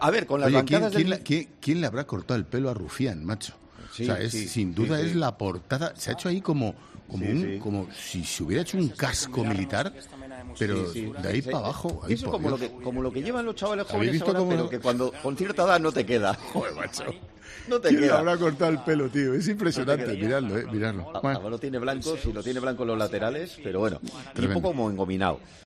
"Pero, ¿lo habéis visto? Joe, macho..." comenzaba riéndose Expósito, como puedes escuchar en este audio.